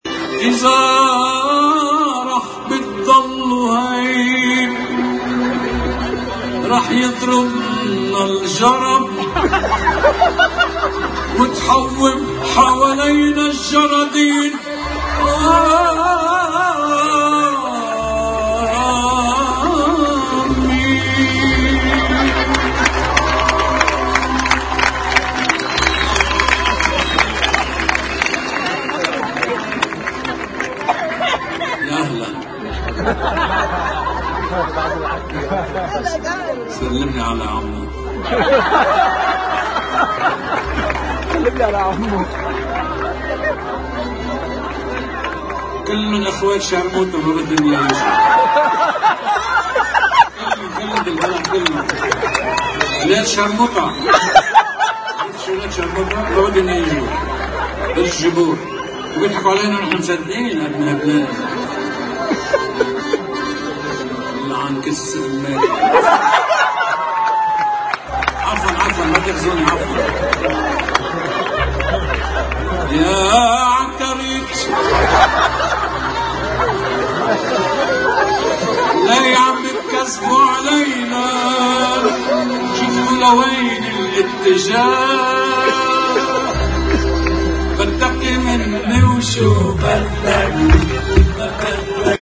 الجدير بالذكر أنّ الفنان الراحل كان قد قال بإحدى حفلاته، أنّه لا أحد من السياسيين يريد انتخاب العماد عون رئيساً…